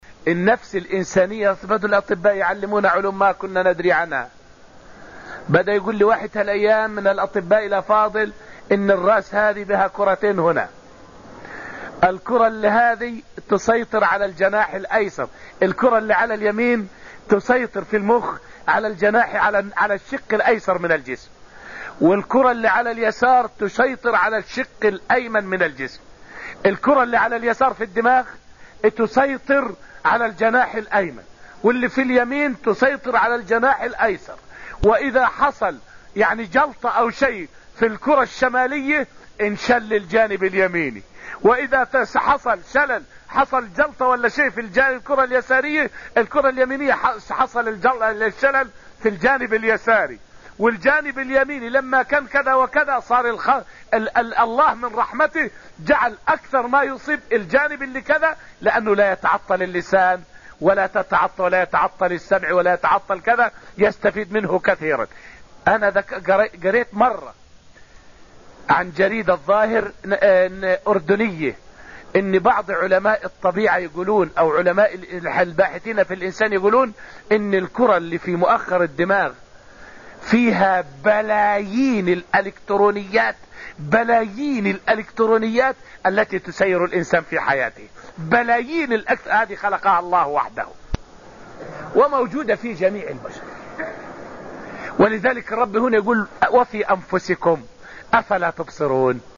فائدة من الدرس الأول من دروس تفسير سورة الذاريات والتي ألقيت في المسجد النبوي الشريف حول جانب من آيات الله في رأس الإنسان.